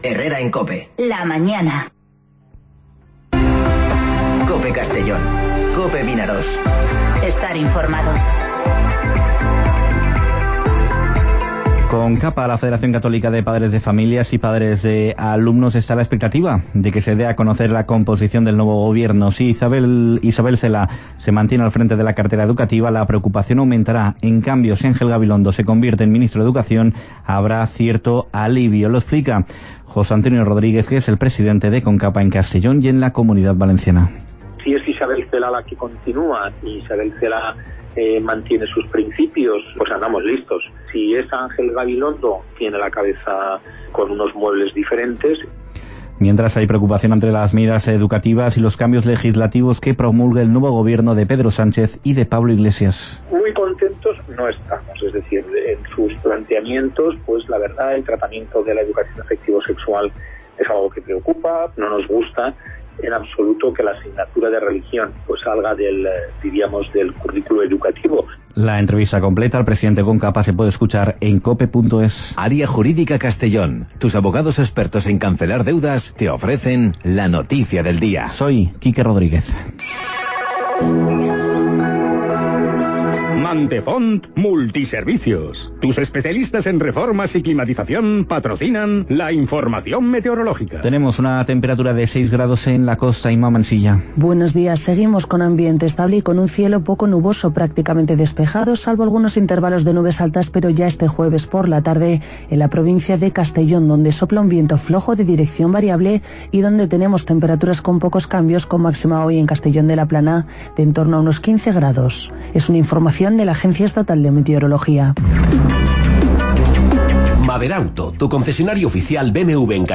Informativo Herrera en COPE Castellón (09/01/2020)